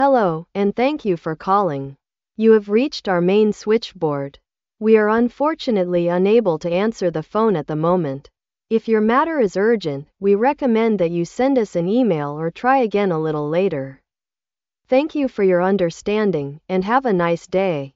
Lydmelding Eksempler
Her er noen eksempler på lydmeldinger som kan brukes med tekst-til-tale.
Generic_Message.mp3